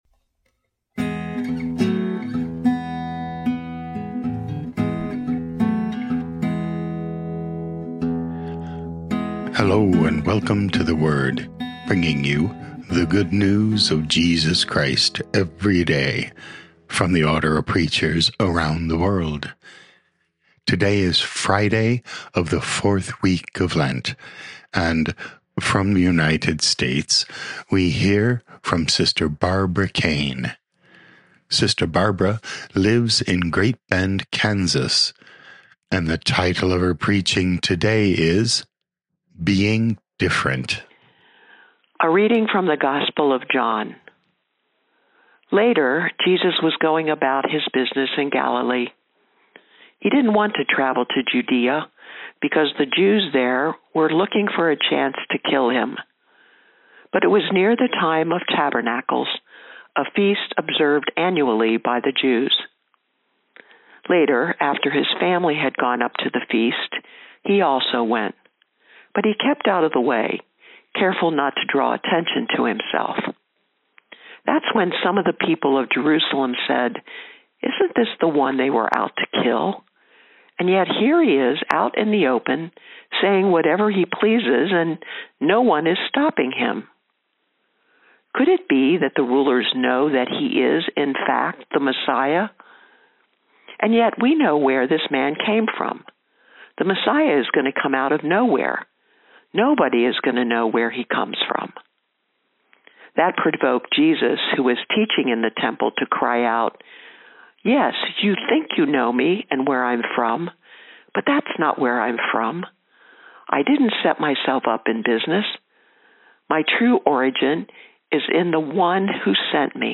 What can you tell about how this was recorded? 20 Mar 2026 Being Different Podcast: Play in new window | Download For 20 March 2026, Friday of the 4th week of Lent, based on John 7:1-2,?10,?25-30, sent in from Great Bend, Kansas, USA.